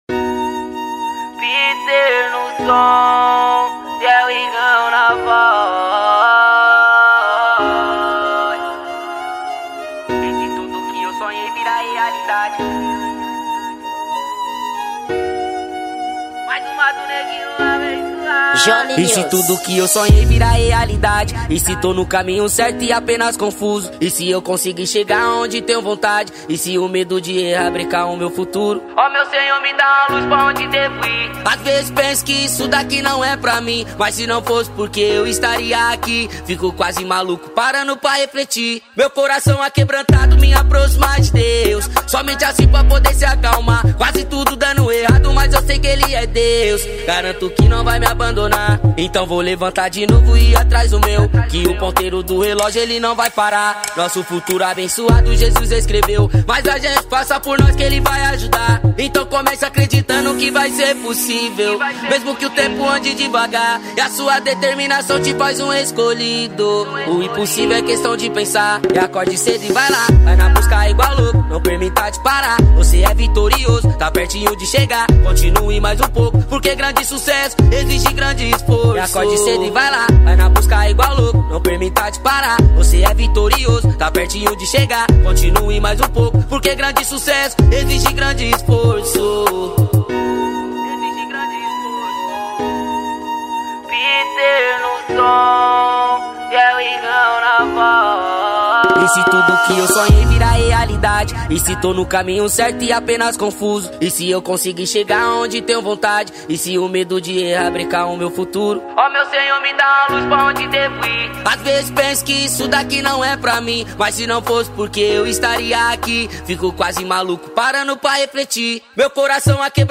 Gênero: Trap Funk